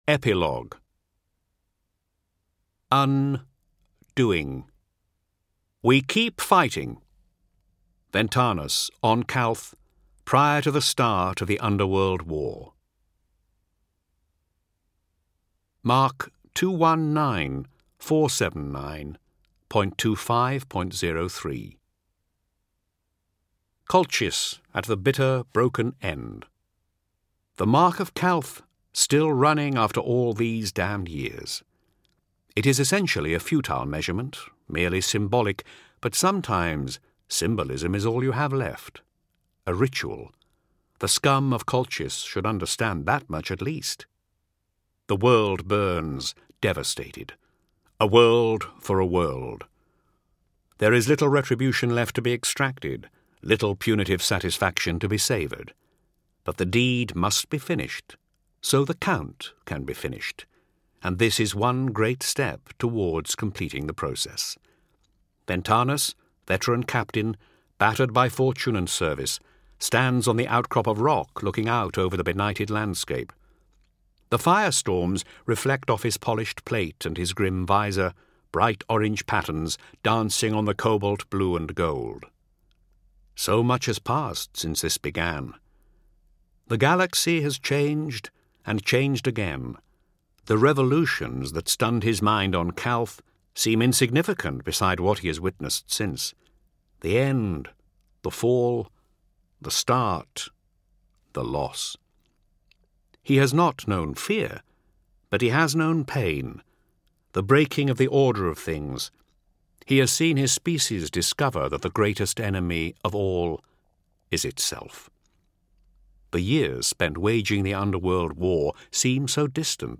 Games/MothTrove/Black Library/Horus Heresy/Audiobooks/The Complete Main Series/HH 11-20/19